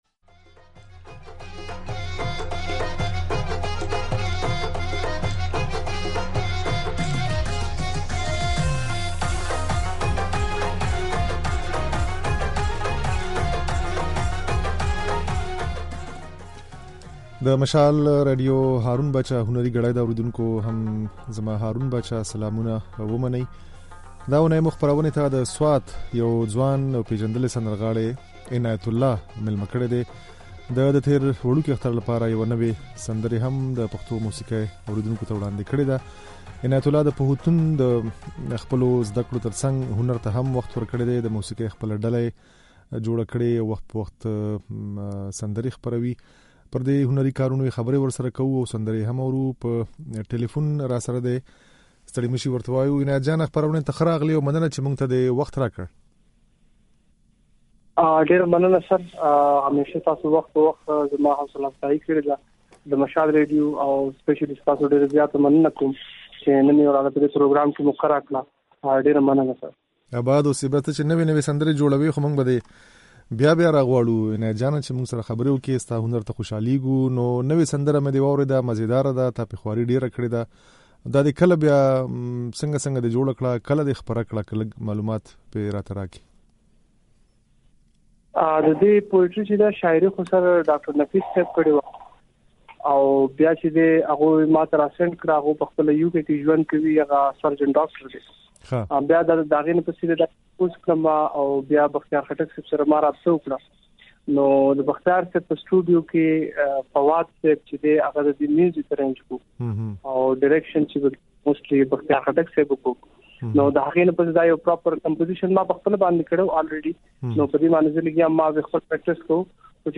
د نوموړي دا خبرې او د نوې سندرې ترڅنګ يې ځينې پخوانۍ هغه هم د غږ په ځای کې اورېدای شئ.